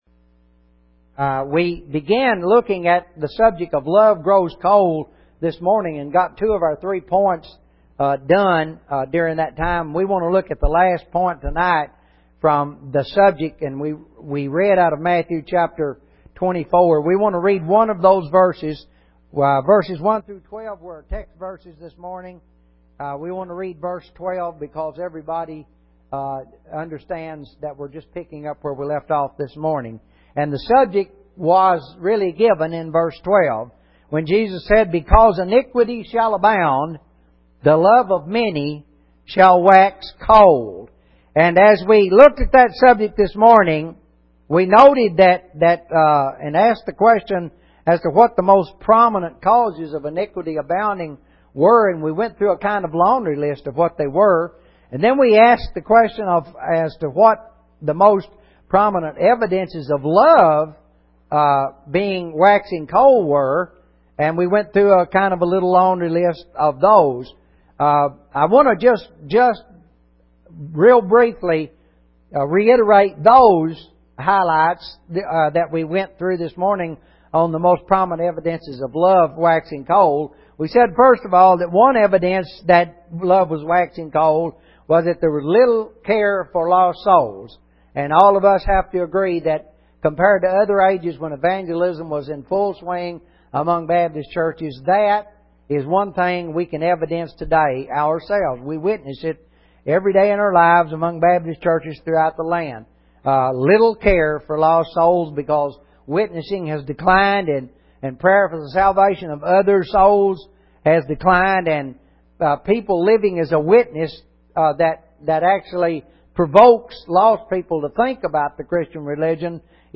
Clicking on an item will open an audio sermon on the subject.